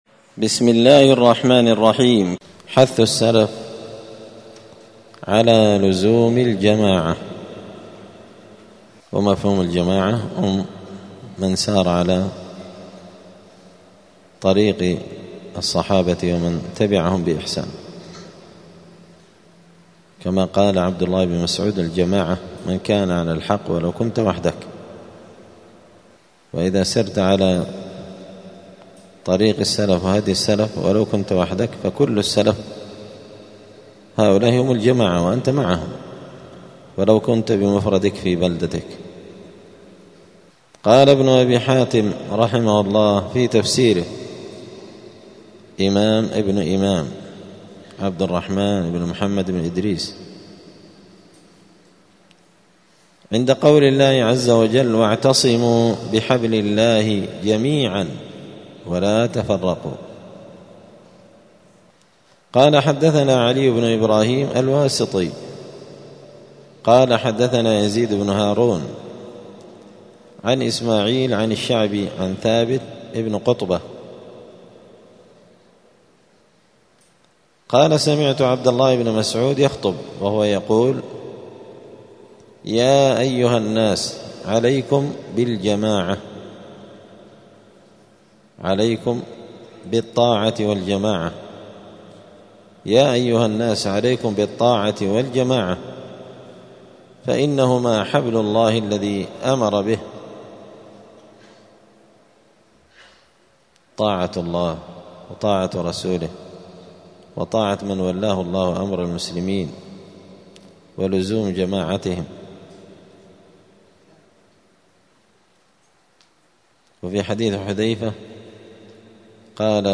دار الحديث السلفية بمسجد الفرقان بقشن المهرة اليمن
*الدرس السادس والستون (66) {باب حث السلف على لزوم الجماعة}*